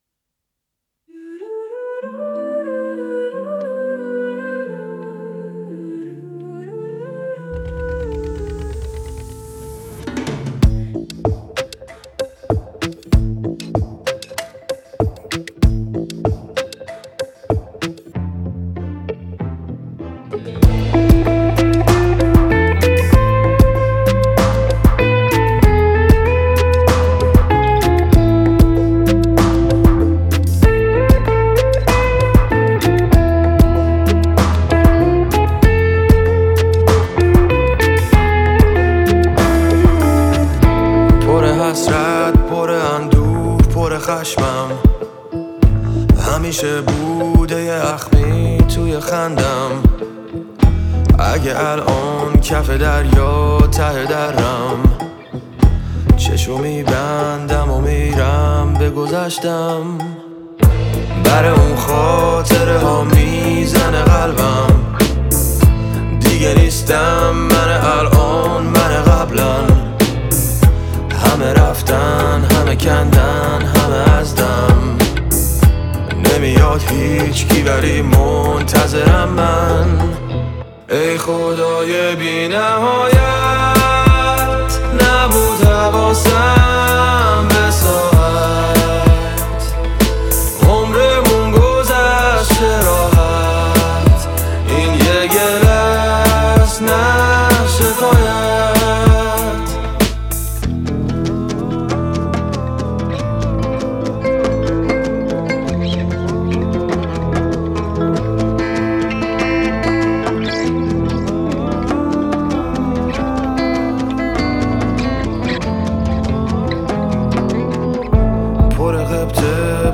اهنگ جدید رپ فارسی